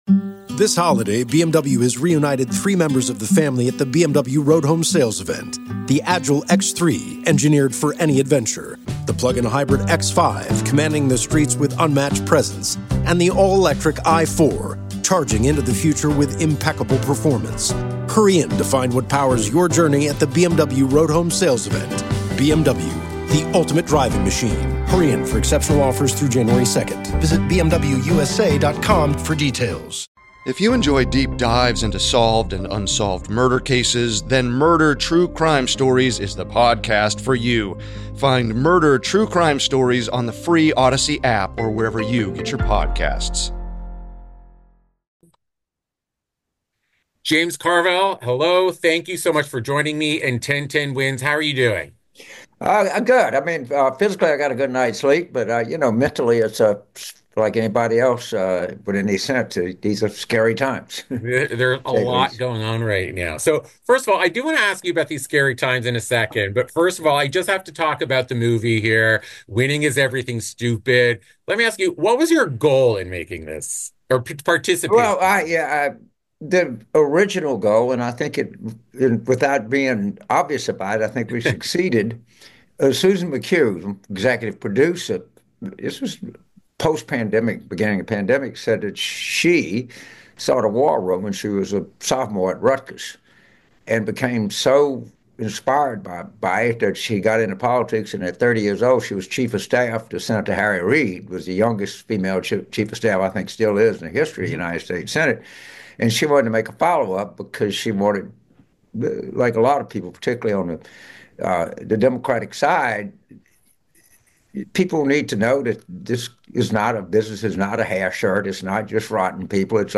Democratic strategist James Carville -- aka the Ragin' Cajun -- speaks